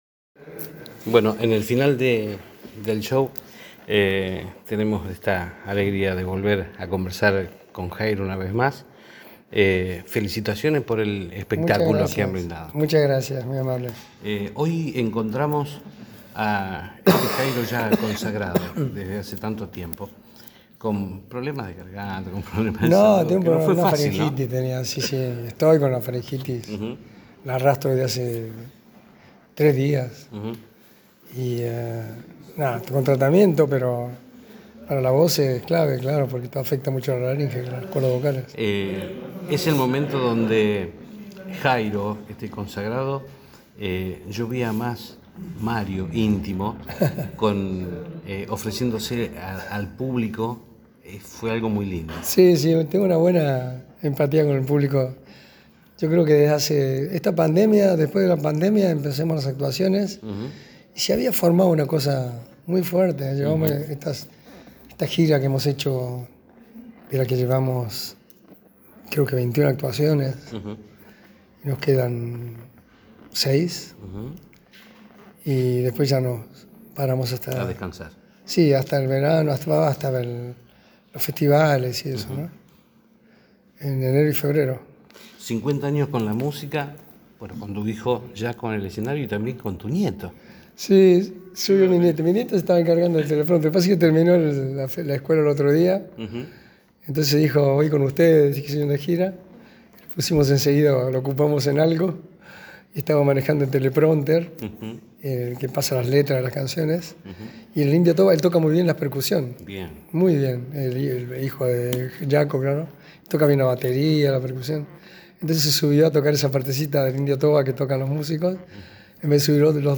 Nota exclusiva con Jairo luego de la actuación en Armstrong